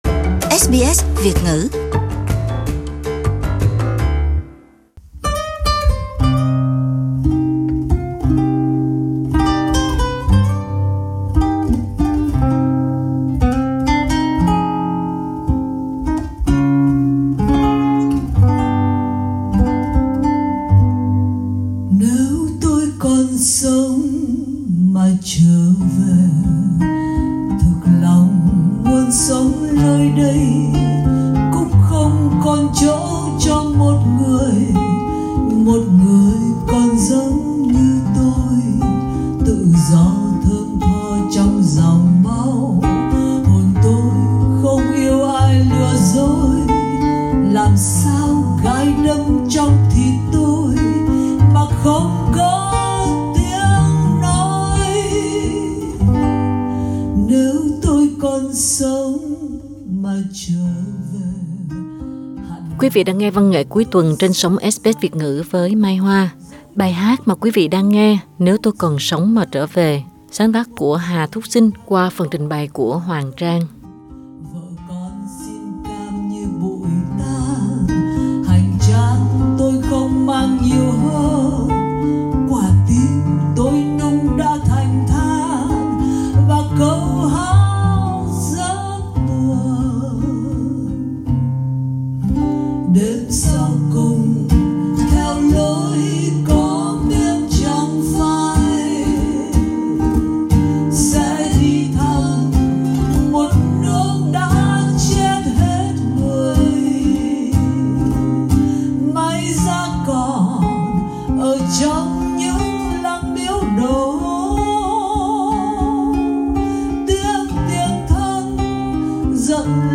Hai bài ngục ca của hai tù nhân Cộng Sản khác cũng sáng tác trong thời gian ở tù do ha igiọng ca nữ ở Melbourne trình bày.